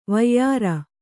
♪ vayyāra